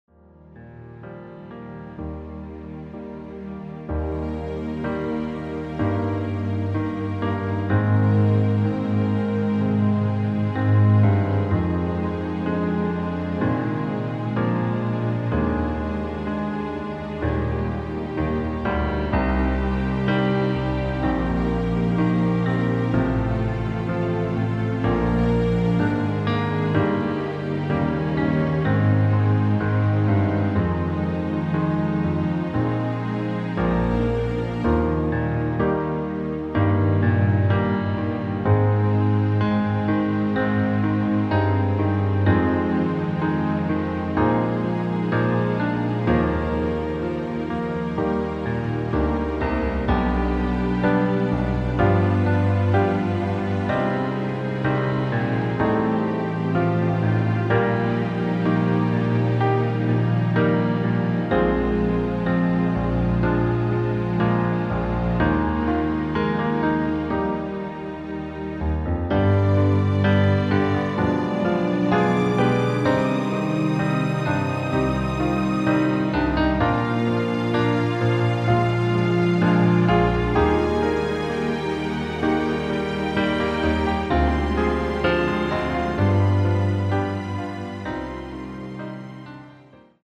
• Tonart:  Eb Dur, F Dur, G Dur
• Art: Klavierversion mit Streichern
• Das Instrumental beinhaltet NICHT die Leadstimme
Klavier / Streicher